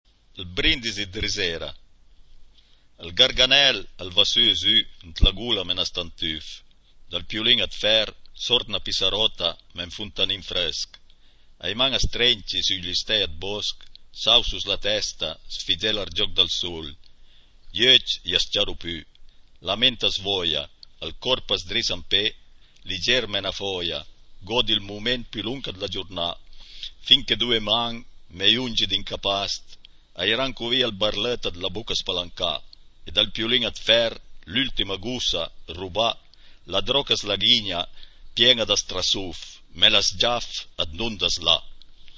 cliché chi par sénti la puizìa recità da l'autùr